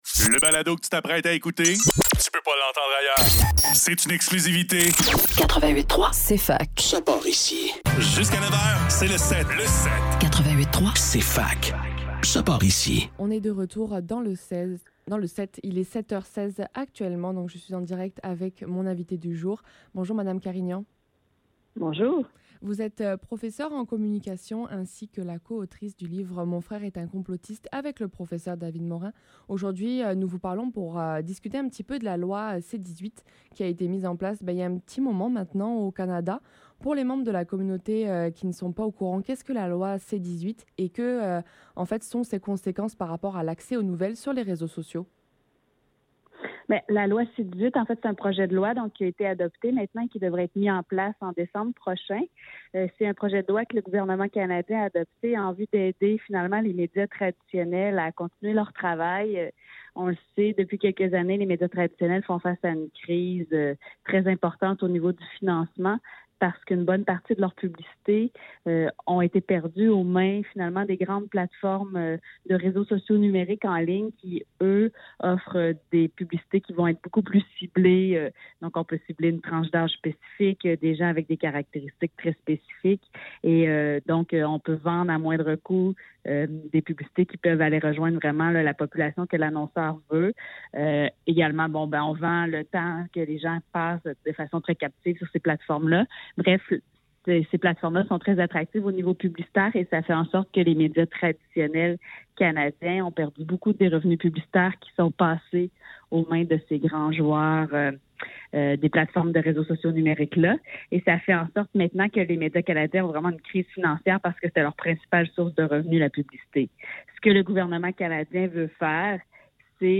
Le SEPT - Entrevue